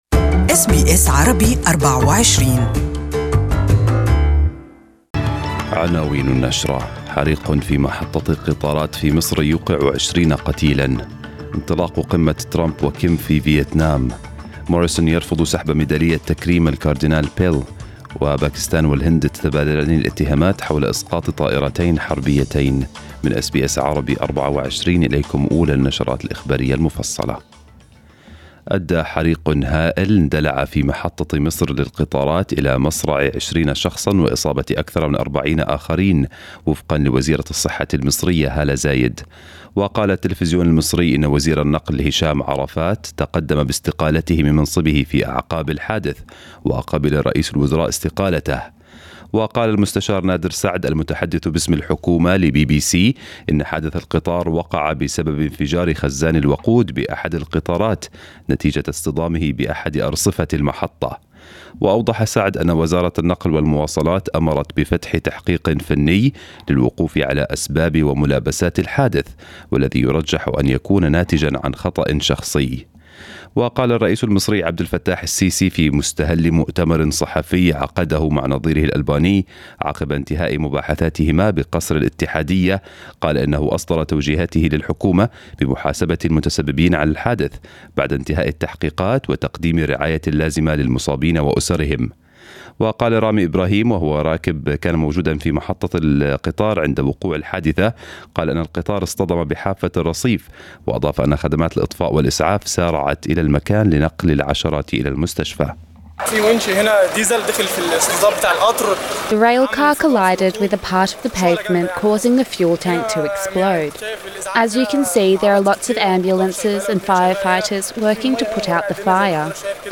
News bulletin in Arabic for the morning